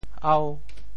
喉 部首拼音 部首 口 总笔划 12 部外笔划 9 普通话 hóu 潮州发音 潮州 ao5 文 中文解释 喉〈名〉 喉头 [larynx;throat] 喉,咽也。